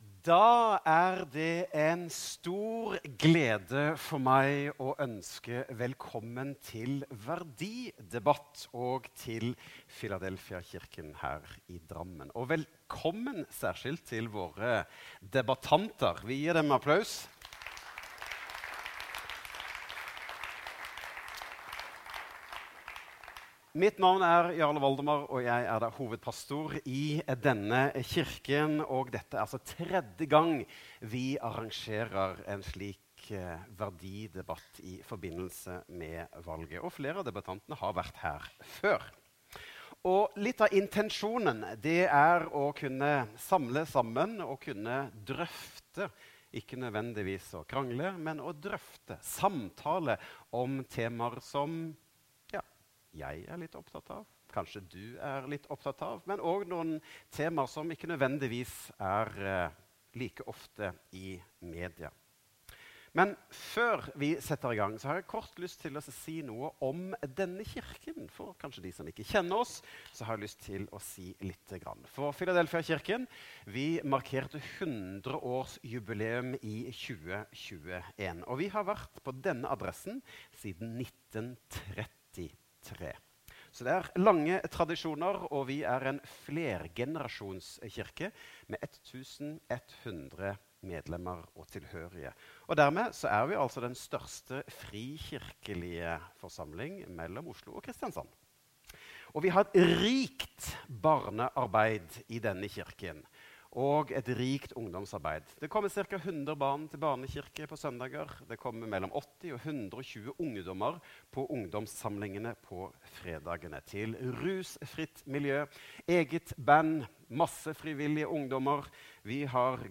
Taler: Politikere fra flere partier